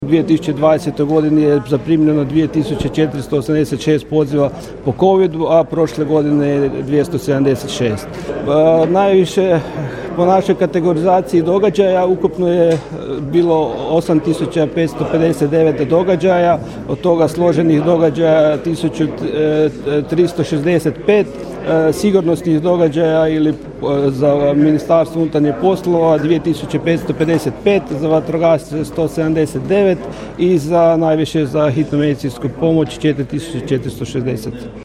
Dan broja 112, sjednica Stožera civilne zaštite MŽ